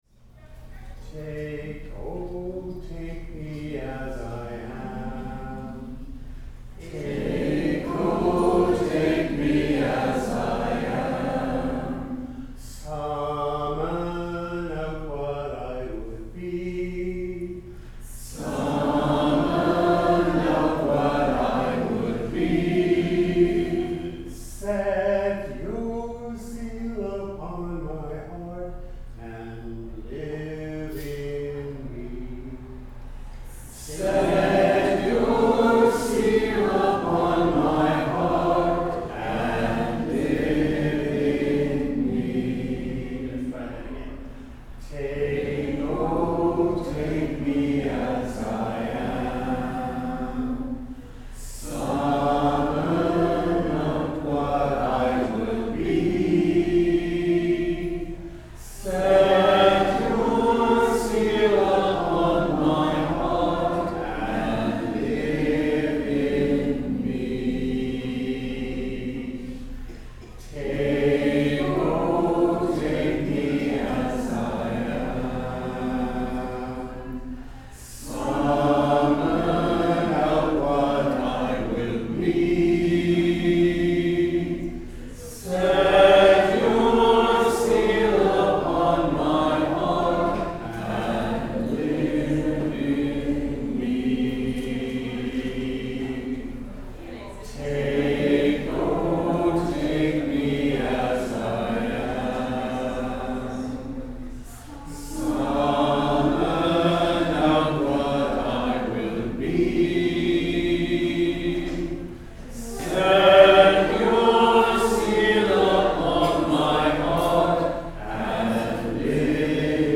Sermons | St John the Evangelist
SEVENTH SUNDAY AFTER PENTECOST Opening Song: Take, O Take Me as I Am Hymn: Lord, You Give the Great Commission (Common Praise #433 – words below) Greeting, Land Acknowledgment, Collect of the Day First Reading: Ezekiel 2:1-5 (reading in French) Psalm 123: Our eyes are fixed on the Lord,...